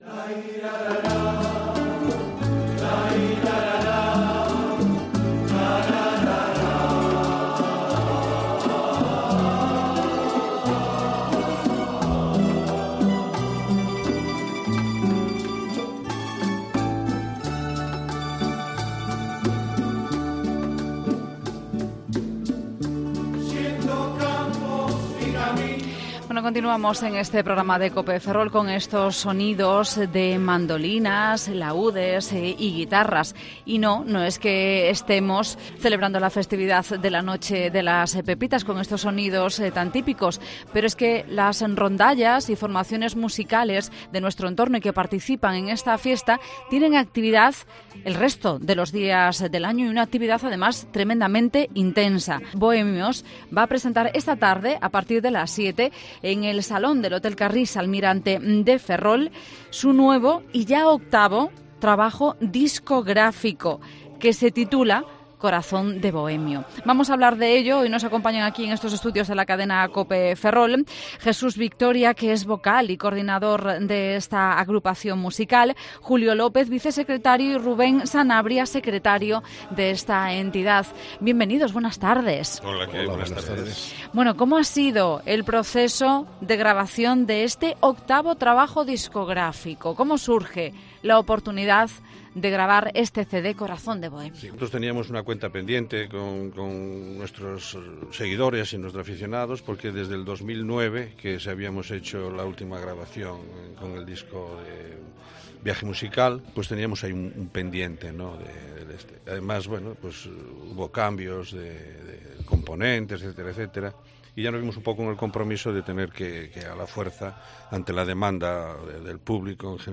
Entrevista a integrantes de Bohemios